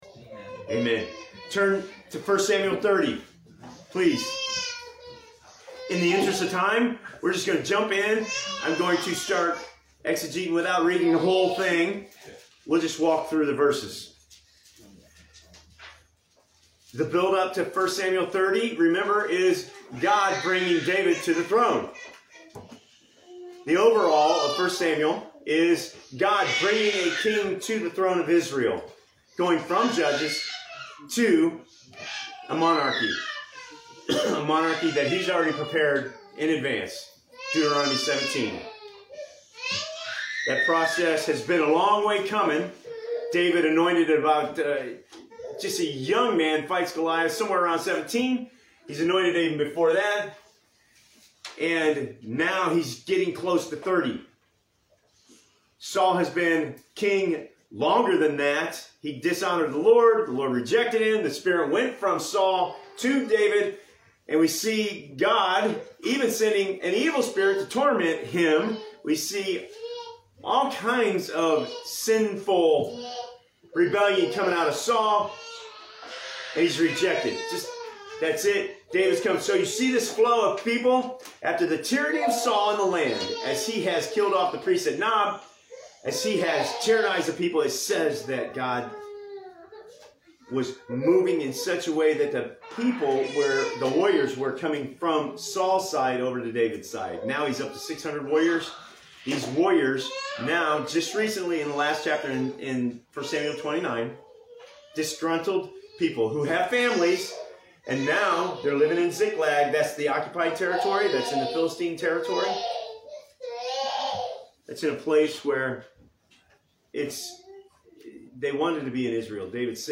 February 28, 2021 Pain to Plunder Passage: 1 Samuel 30.1-21 Service Type: Morning Worship Service What are we to do when tragedy hits?